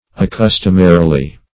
accustomarily - definition of accustomarily - synonyms, pronunciation, spelling from Free Dictionary Search Result for " accustomarily" : The Collaborative International Dictionary of English v.0.48: Accustomarily \Ac*cus"tom*a*ri*ly\, adv.